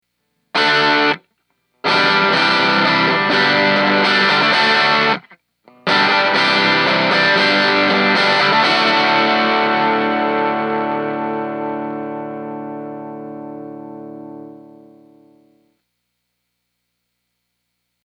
Here’s a raw clip of the Champ completely cranked up:
58_dirty_raw.mp3